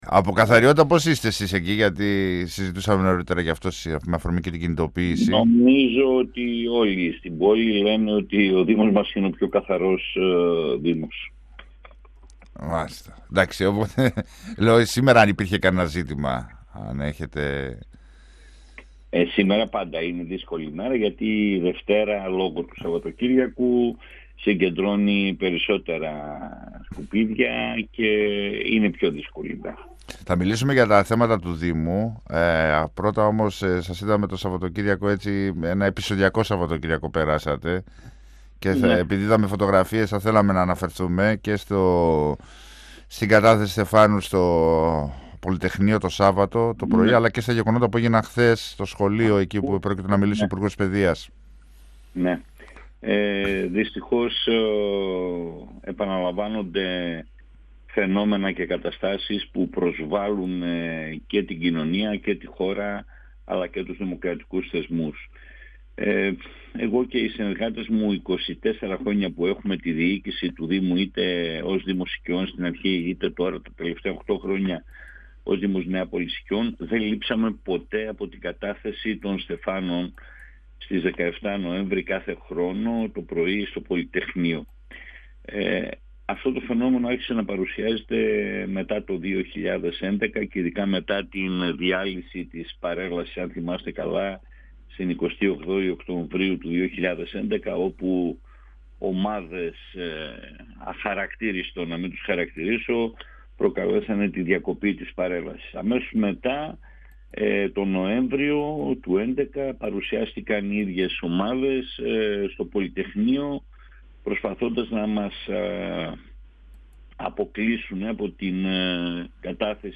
Μειώσεις στα δημοτικά τέλη καθαριότητας και ηλεκτροφωτισμού έως 10% ενέκρινε το δημοτικό συμβούλιο Νεάπολης Συκεών τις προηγούμενες ημέρες. Η απόφαση δεν έχει προεκλογικό χαρακτήρα ανέφερε ο δήμαρχος Σίμος Δανιηλίδης, μιλώντας στον 102FM του Ραδιοφωνικού Σταθμού Μακεδονίας της ΕΡΤ3 και υπενθύμισε ότι τα προηγούμενα χρόνια είχαν υπάρξει ανάλογες κινήσεις με αποτέλεσμα ο δήμος να είναι πλέον ο φθηνότερος όσον αφορά τα ανταποδοτικά τέλη, σε όλη τη χώρα.
Συνέντευξη